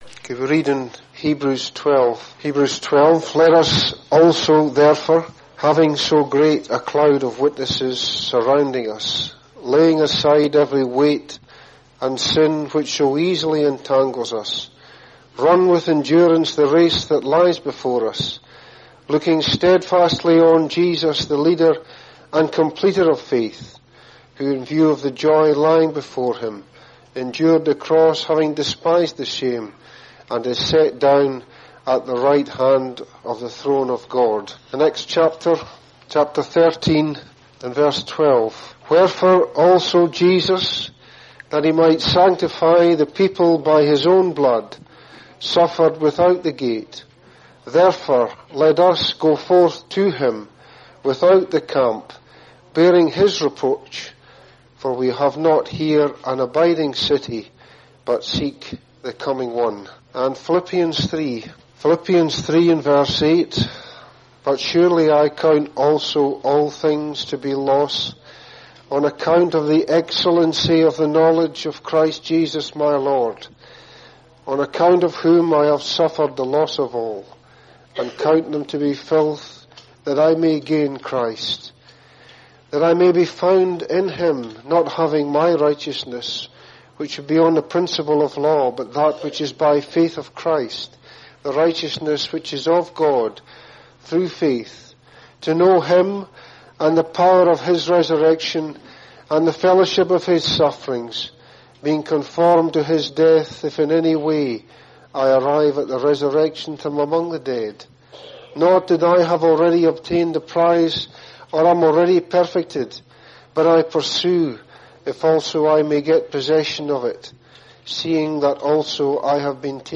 Listen to this address to learn from God's word about the importance of running the race with our eyes fixed on Jesus.